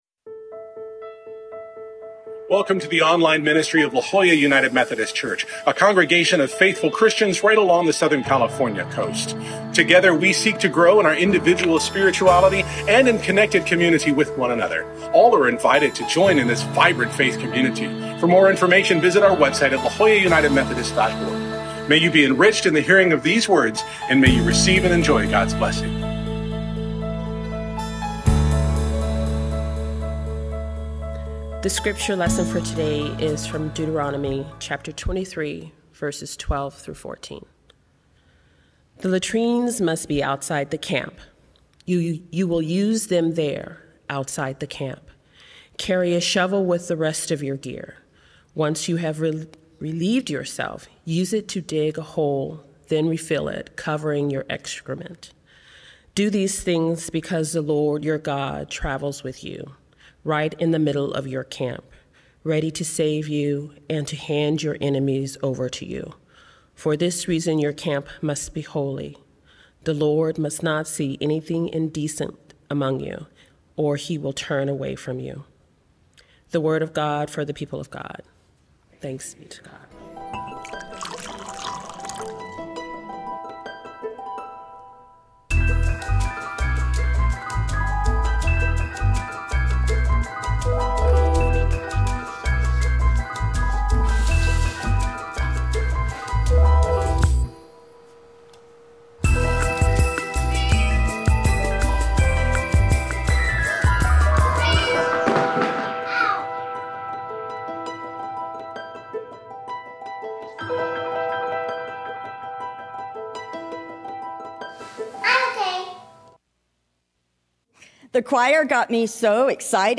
Scripture: Deuteronomy 23:12-14 (CEB) worship bulletin Sermon Note Full Worship Video Share this: Print Twitter Facebook Audio (M4A) 15 MB Previous God Won’t Give You More Than You Can Handle?